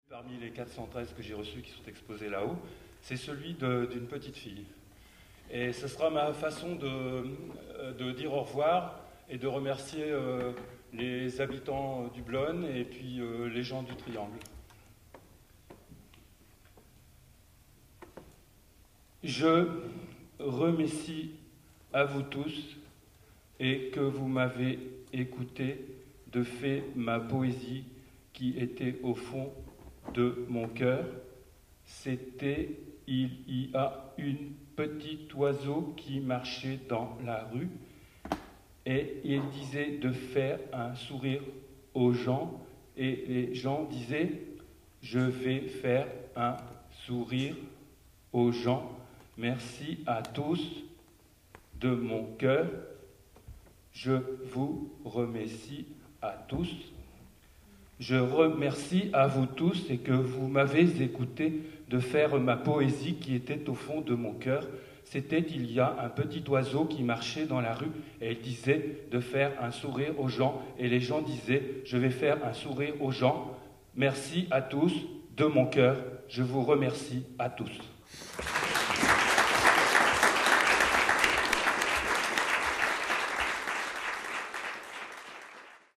Au bout de la langue - Lectures publiques au triangle